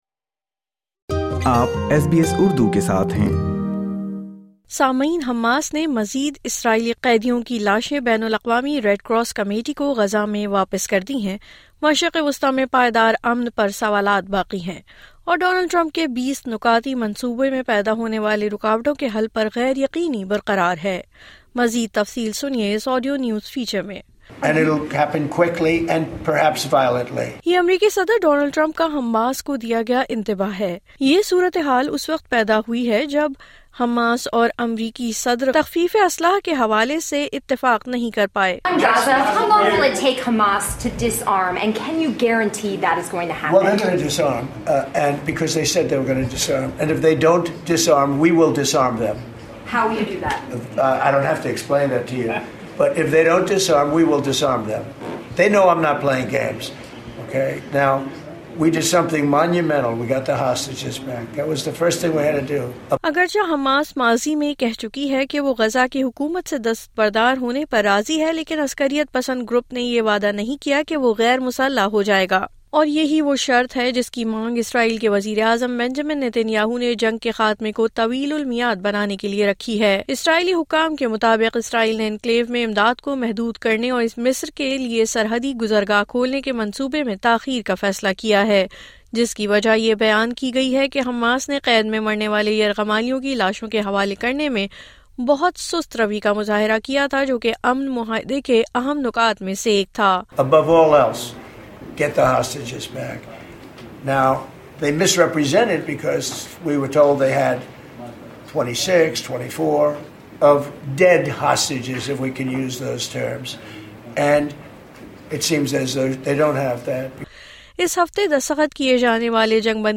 مزید تفصیل اس آڈیو نیوز فیچر میں